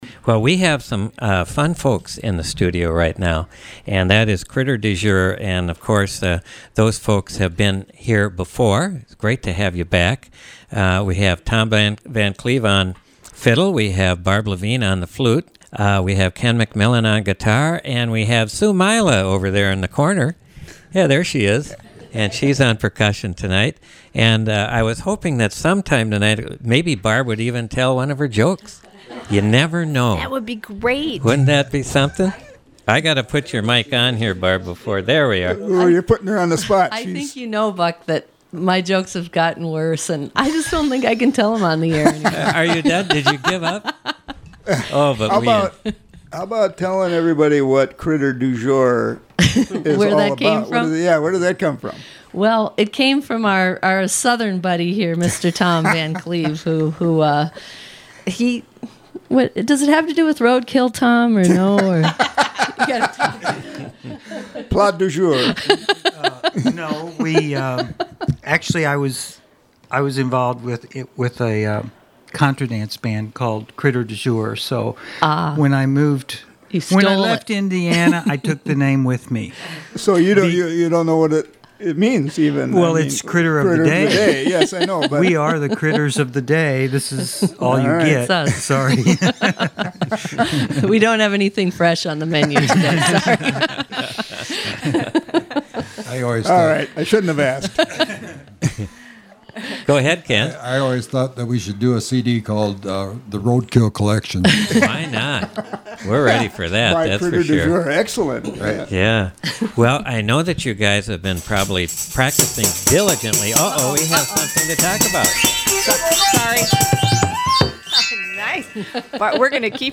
to Studio A during the recent "All Welcome" membership drive
guitar
flute
accordion & fiddle
percussion, made lovely music and lots of laughs.
Live Music Archive